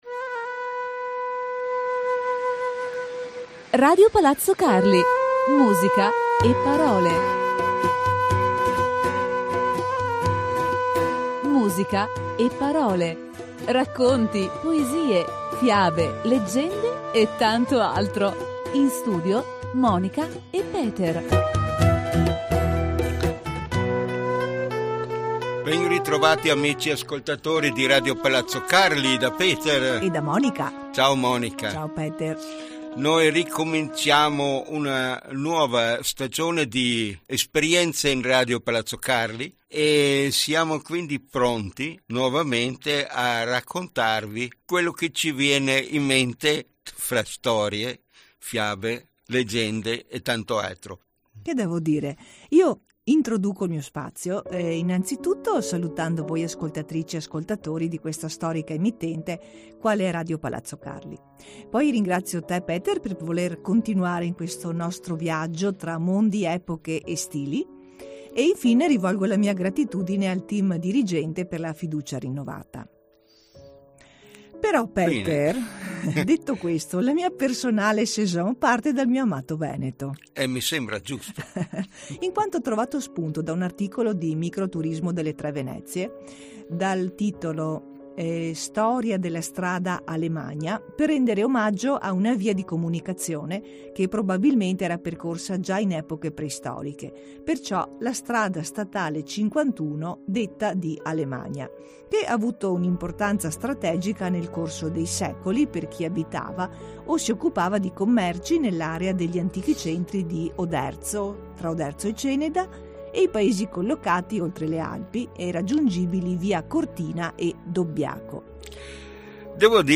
La coppia radiofonica più gettonata di RPC con la loro seguitissima trasmissione di favole, storie, leggende e poesie e tanto altro e, come sempre, il tutto inframmezzato da ottima musica dal mondo.